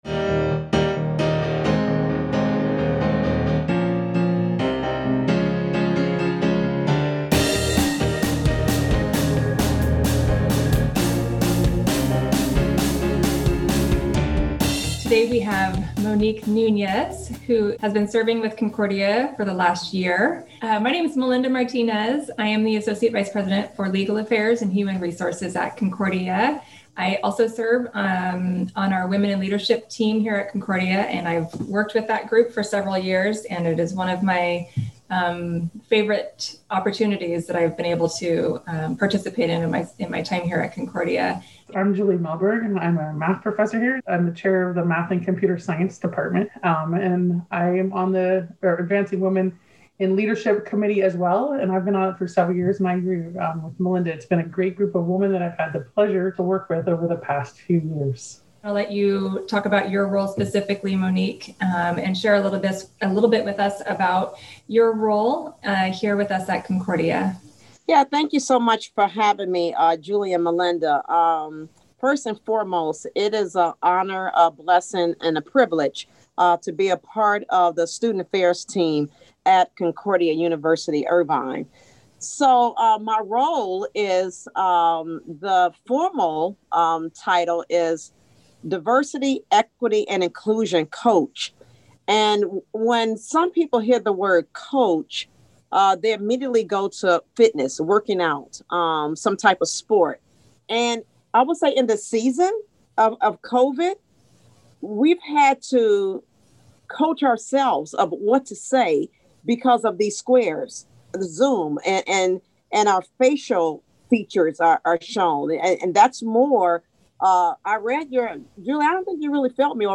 AWiL Interview